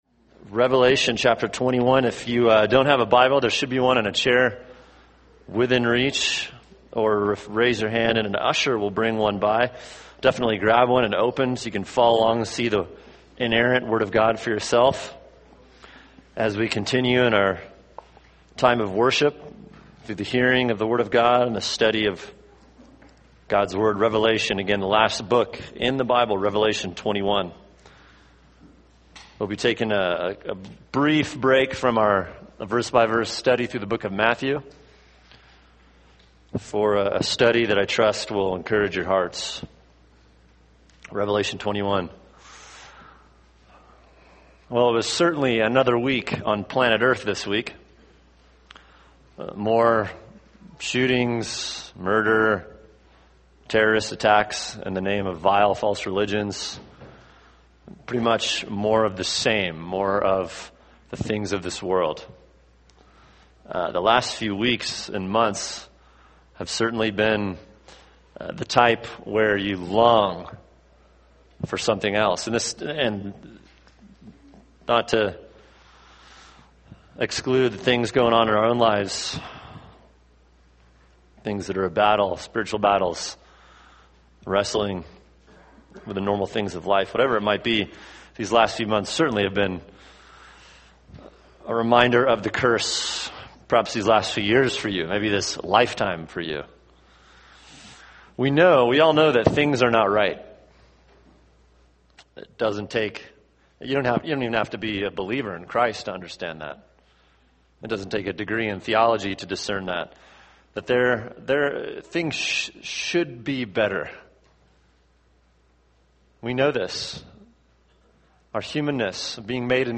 [sermon] Revelation 21 – All Things New (part 1) | Cornerstone Church - Jackson Hole